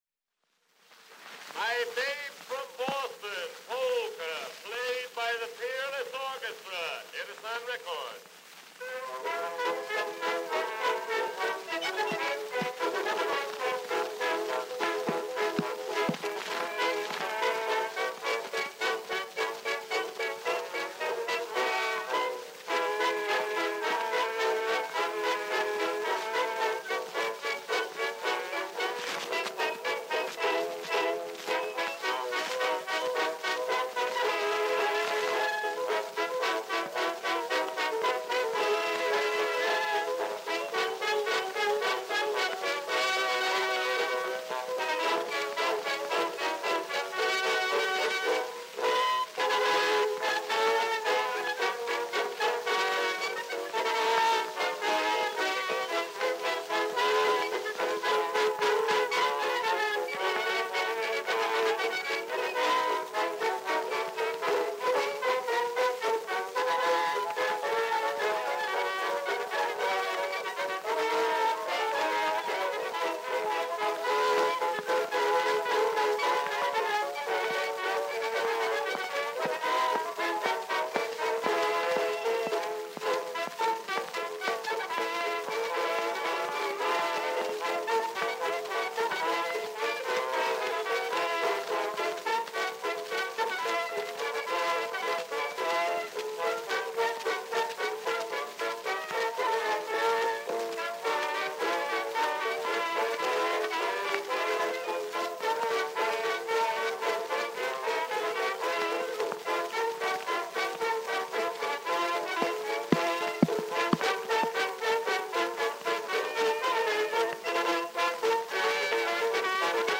Dance music Popular instrumental music